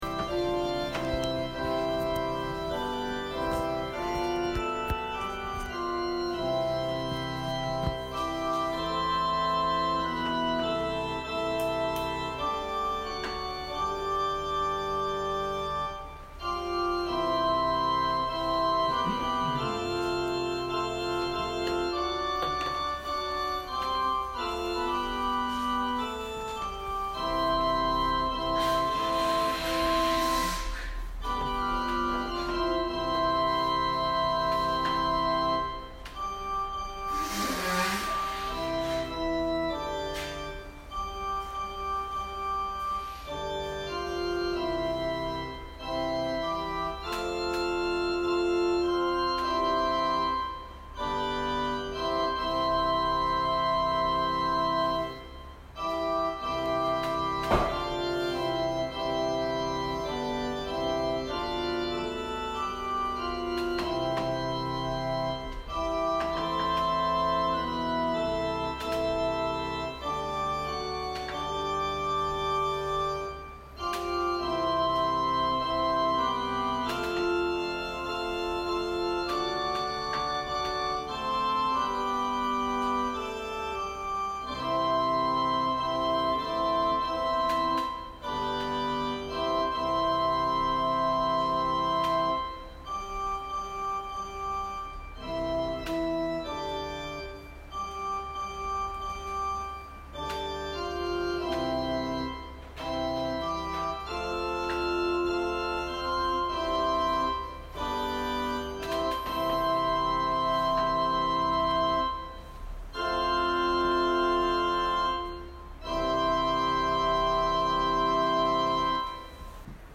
説教アーカイブ。
埼玉県春日部市のプロテスタント教会。
音声ファイル 礼拝説教を録音した音声ファイルを公開しています。